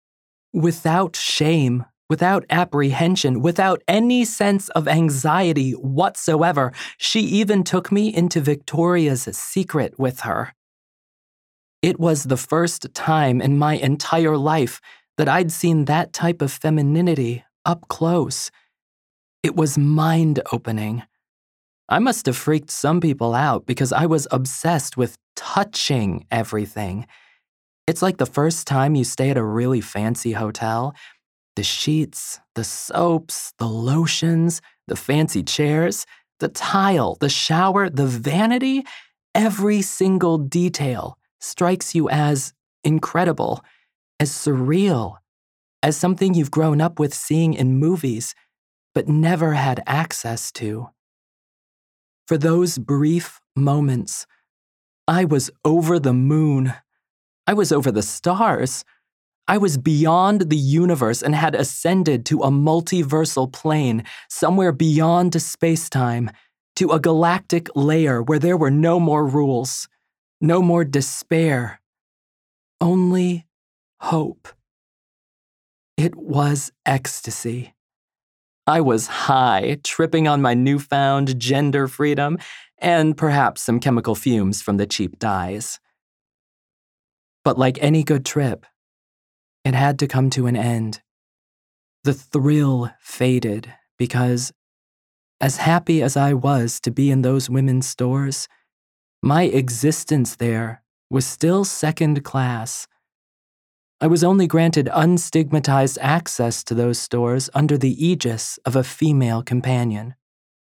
Nonfiction – Memoir (1st person)
Non-Binary Transfeminine POV download